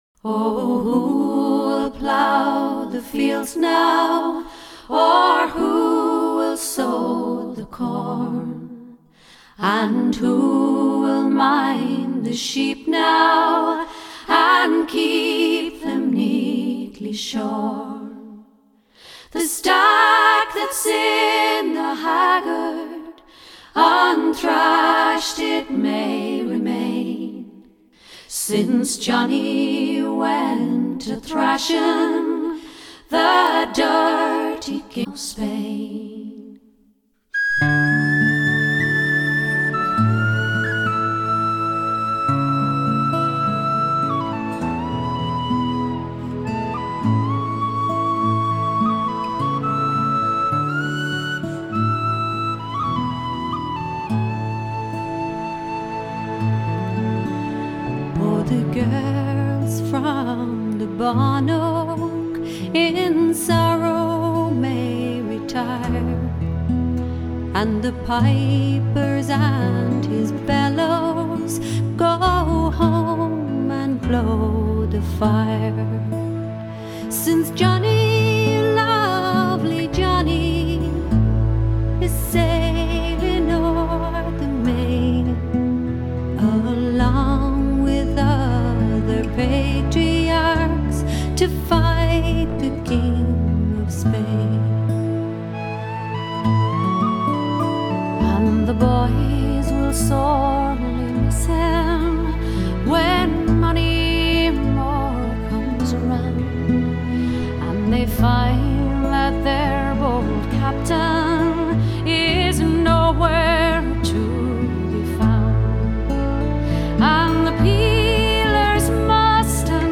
0077-风笛名曲猫叫声Bantry Girls Lament.mp3